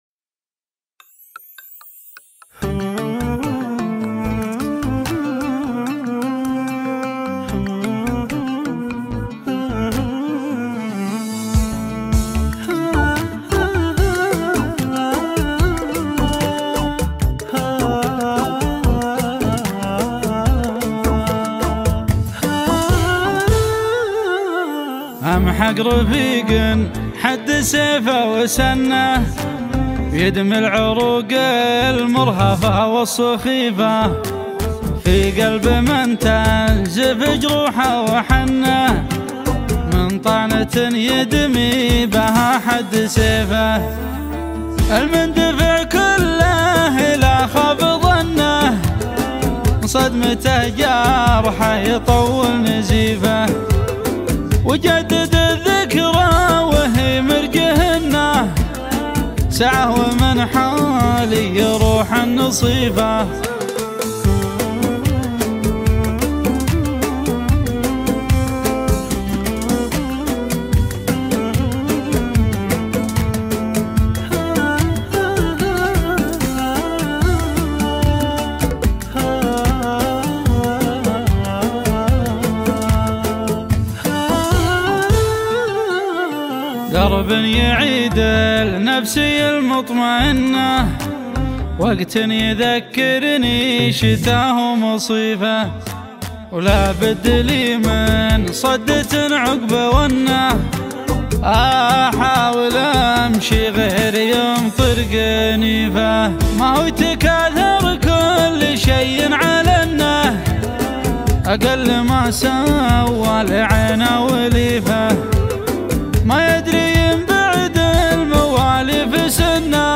شيلات حزينة